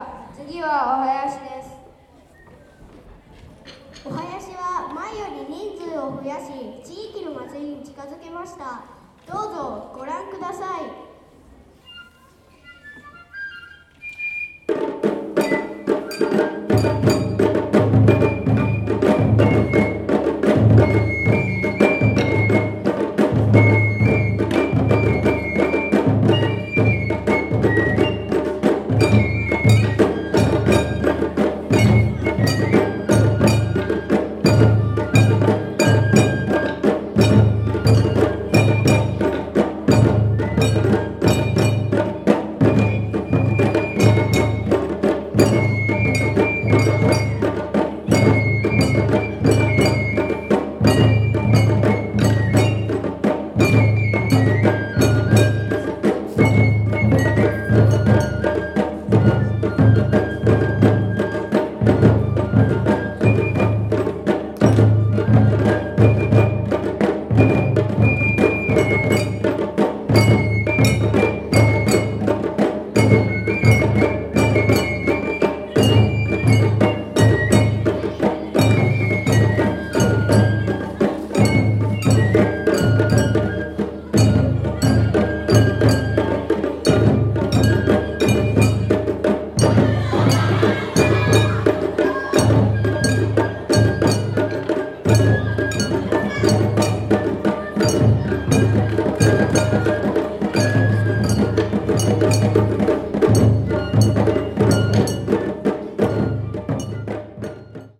御囃子。